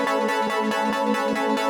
SaS_MovingPad01_140-A.wav